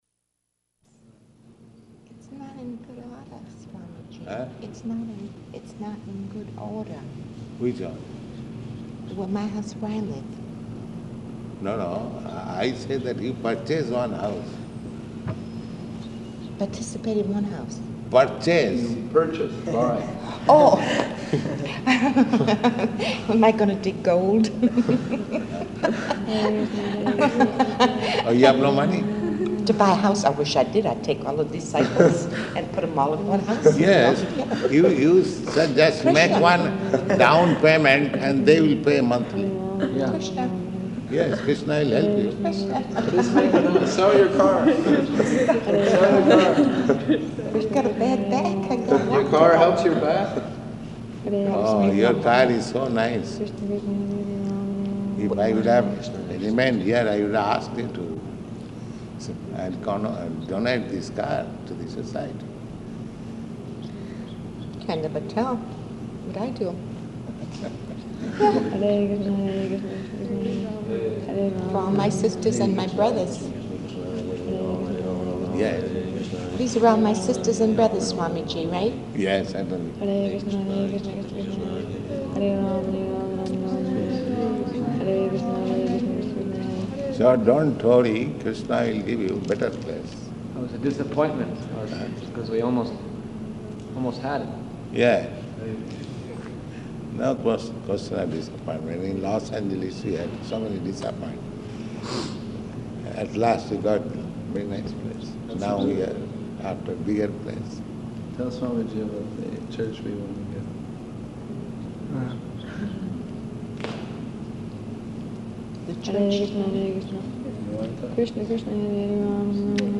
Type: Conversation
Location: Boston
[devotees chanting japa in background throughout]